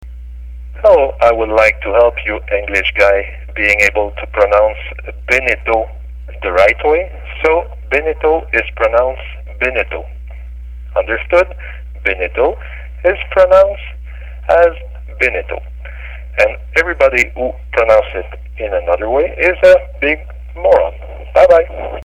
beneteau_pronunciation.mp3